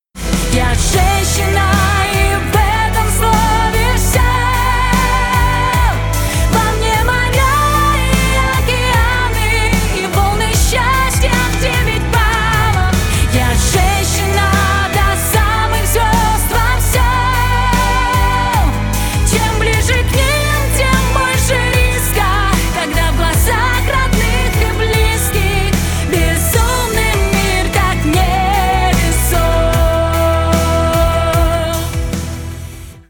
Поп Музыка
громкие